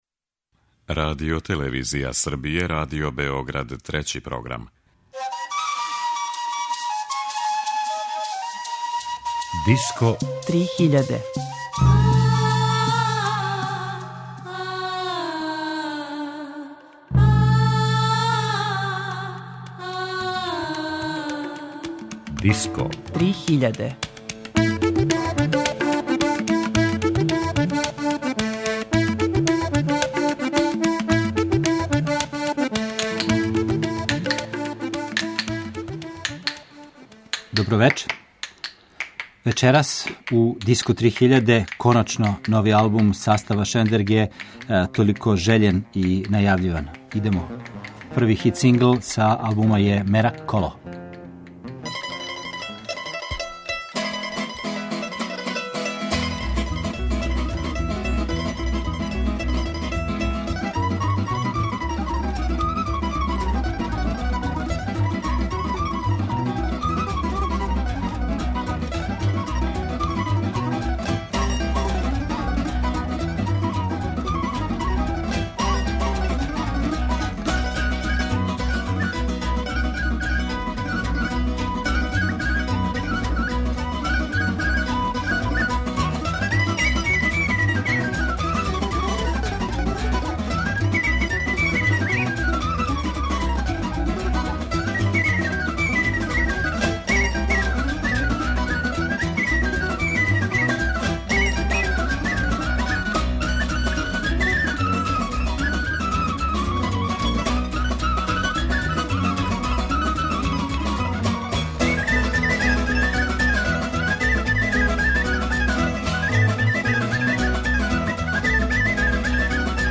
Söndörgő – нови албум и интервју